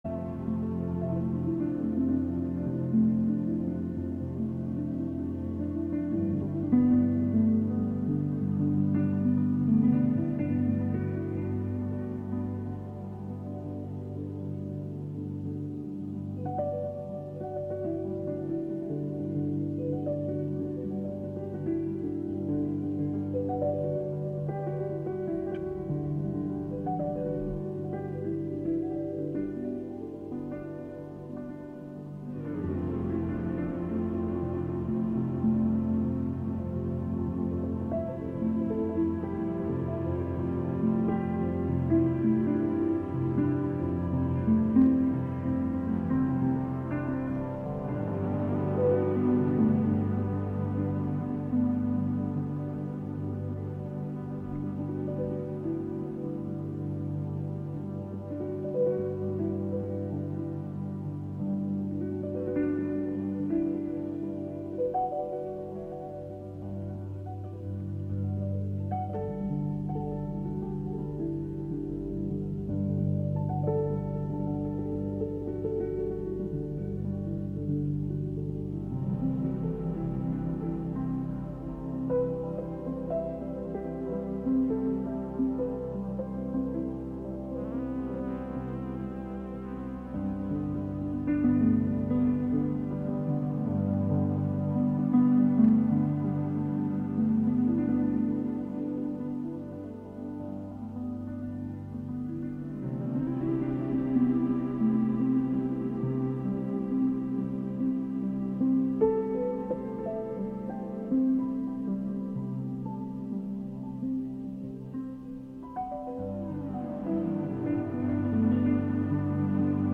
Monthly excursions into music, soundscape, audio document, and spoken word, inspired by the wide world of performance. Live from Ulster County.